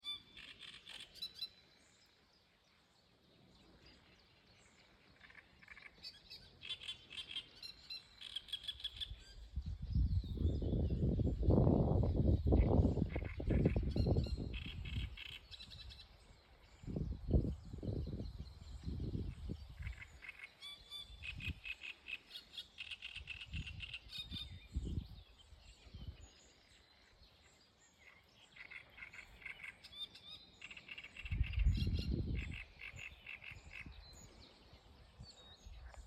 Niedru strazds, Acrocephalus arundinaceus
Administratīvā teritorijaValkas novads
StatussDzied ligzdošanai piemērotā biotopā (D)